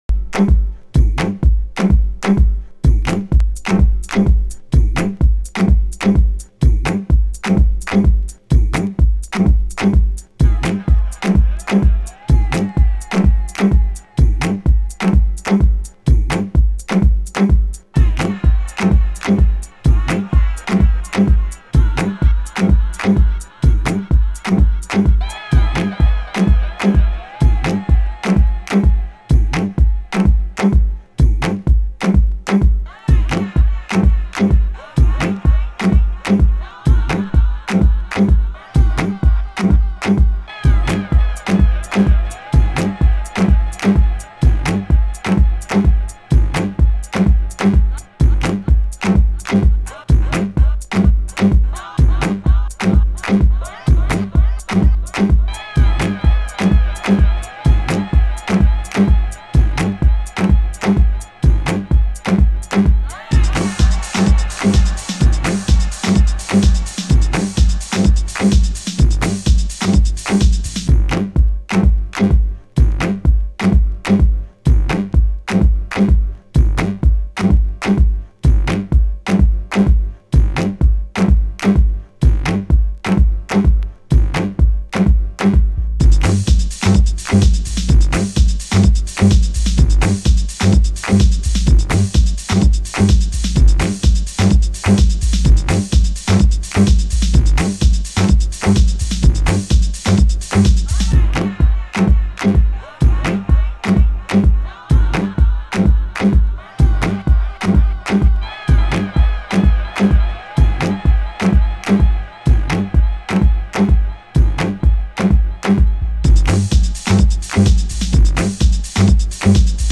(Instrumental)　B1